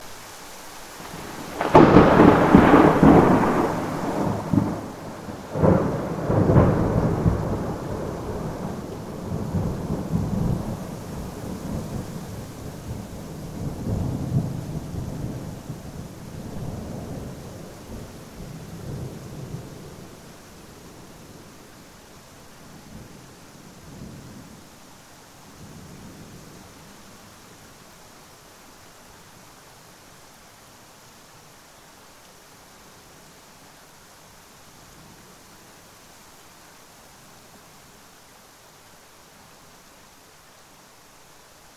Звуки плохой погоды
Грохот раската грома